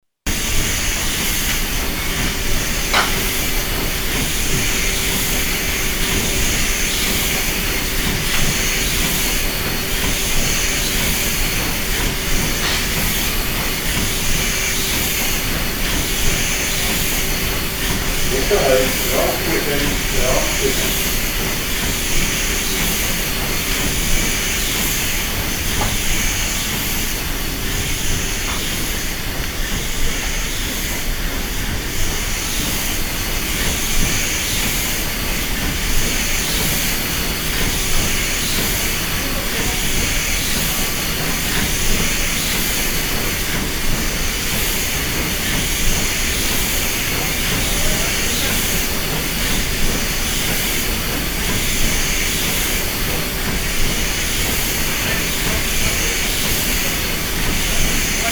MS Gisela mit Durchsage Traunkirchen